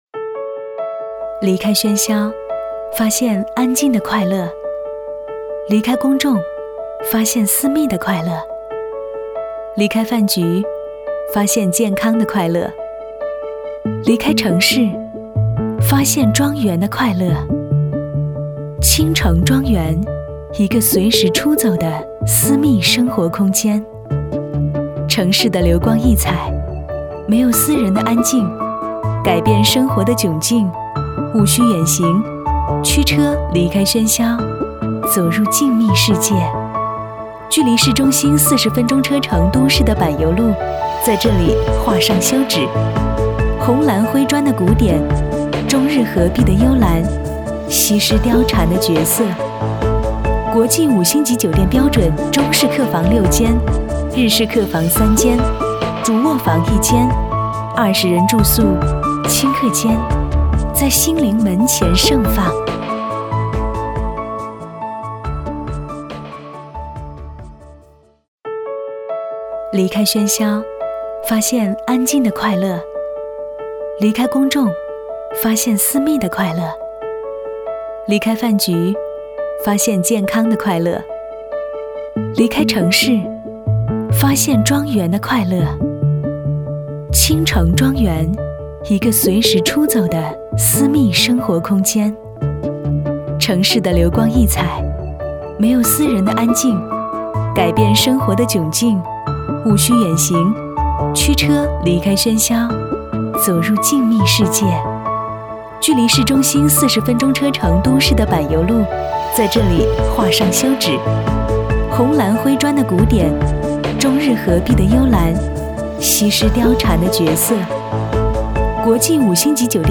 • 女S19 国语 女声 专题片-倾城庄园-时尚专题片-动感 欢快 大气浑厚磁性|沉稳|亲切甜美